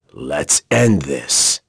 Crow-Vox_Skill4.wav